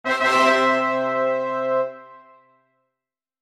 Звук победы в игре Супер